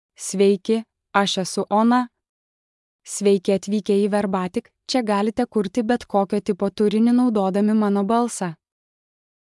OnaFemale Lithuanian AI voice
Ona is a female AI voice for Lithuanian (Lithuania).
Voice sample
Female
Ona delivers clear pronunciation with authentic Lithuania Lithuanian intonation, making your content sound professionally produced.